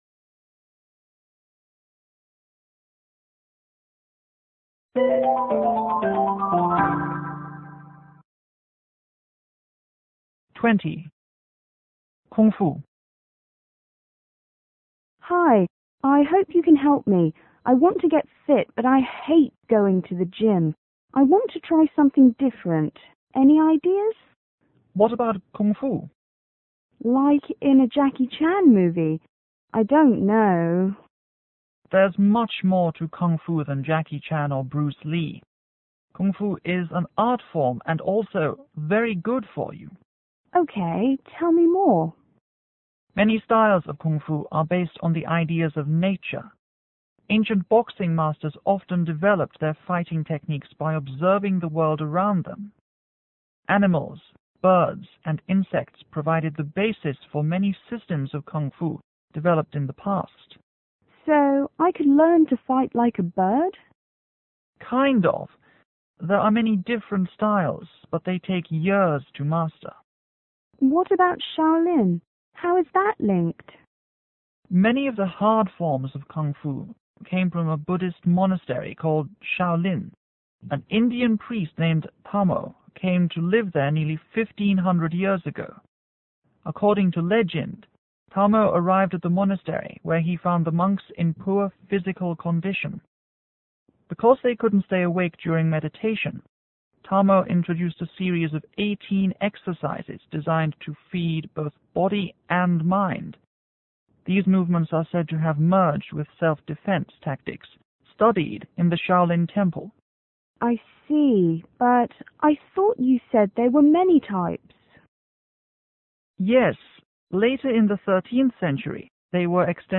S: Student      MT: Martial arts teacher